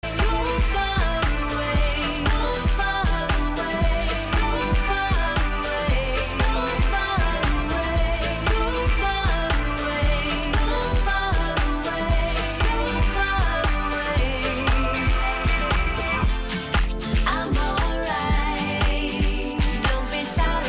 Качество приёма FM-радиостанций заслуживает хорошей оценки.
Тюнер продемонстрировал хорошее качество звука (